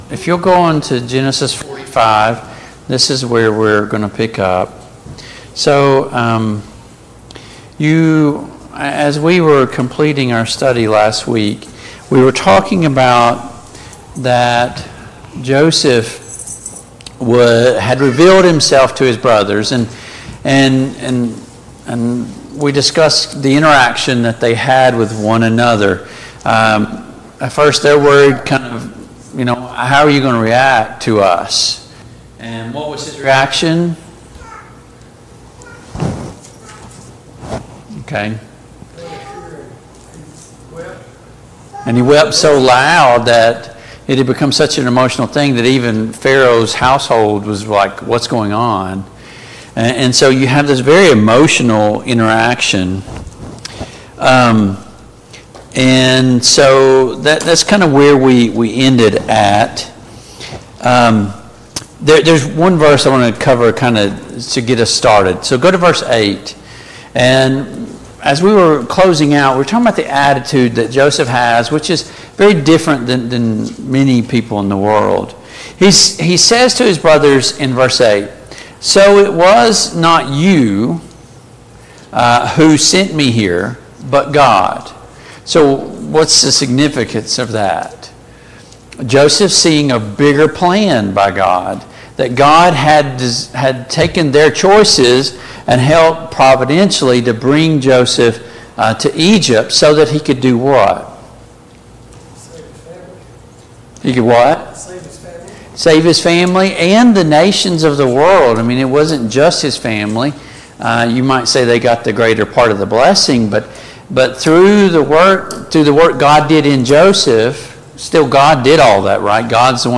Study of Genesis Passage: Genesis 45-47 Service Type: Family Bible Hour Topics